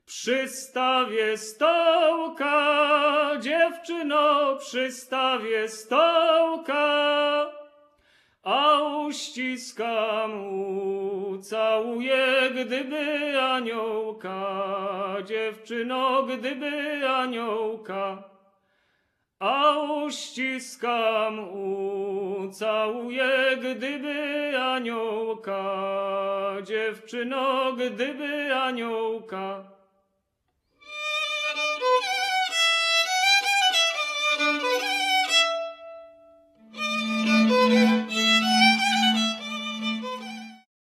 skrzypce fiddle, cymbały dulcimer, śpiew voice
bębenek tambourine, baraban baraban drum, basy bass
flet drewniany wooden flute, szałamaja shawm